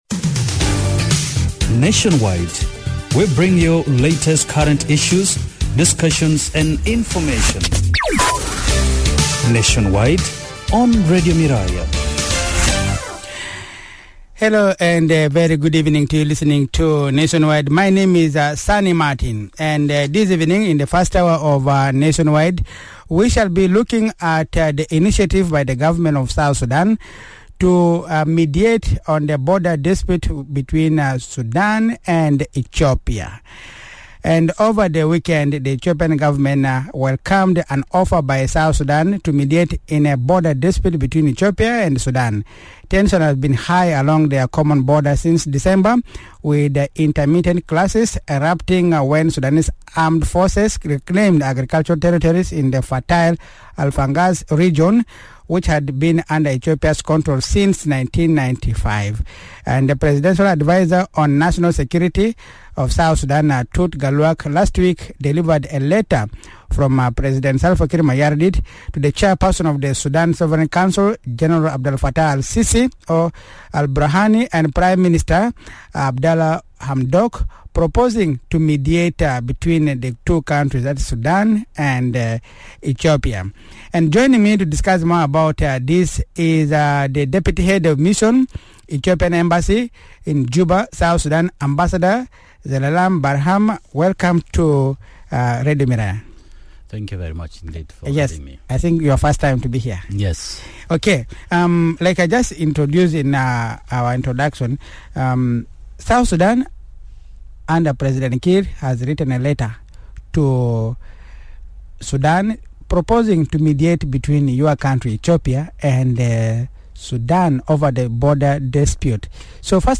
In an exclusive interview with Radio Miraya, Ethiopia’s Deputy Head of Mission to South Sudan Ambassador Zelalem Birhan Alemu said Khartoum took advantage of the internal crisis in Ethiopia to take control of the land.